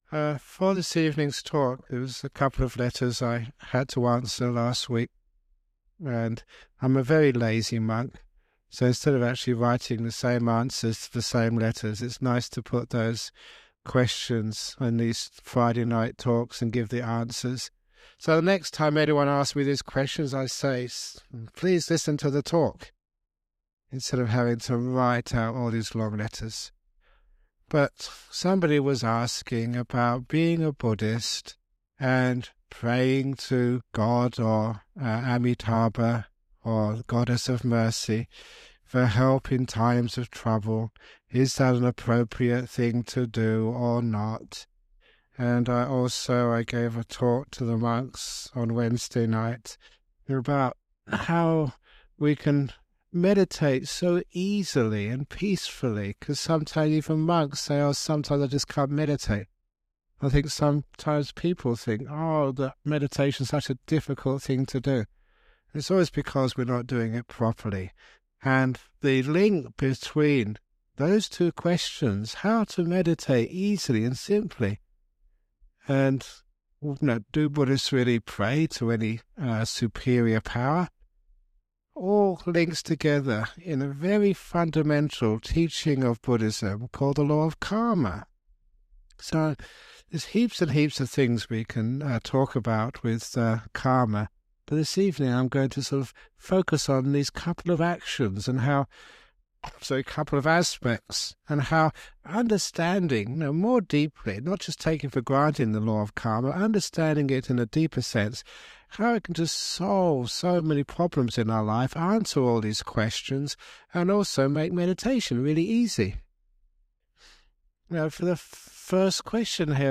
Ajahn Brahm talks about the Law of Karma in response to some questions about praying to God or a divine being for help. He emphasizes that instead of praying for help, we should take action and be responsible for our own lives. Ajahn Brahm also shares a powerful story about how we can use difficult experiences in life as fertilizer to grow and learn from.
It has now been remastered and published by the Everyday Dhamma Network , and will be of interest to his many fans.